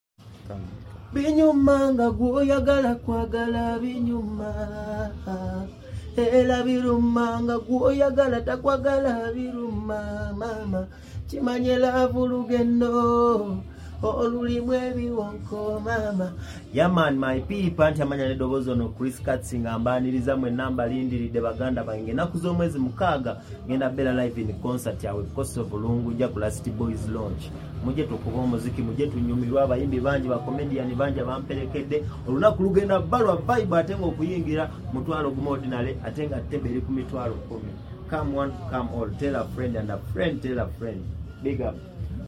Live in Concert